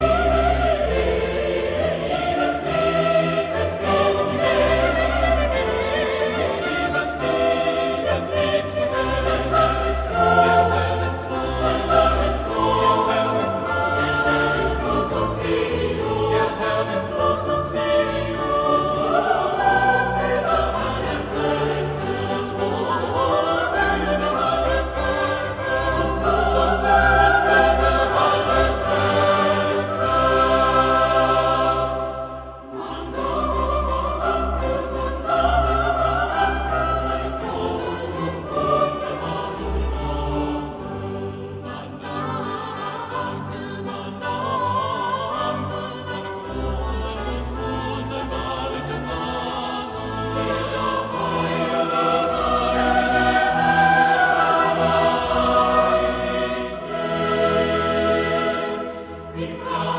• Sachgebiet: Klassik: Chormusik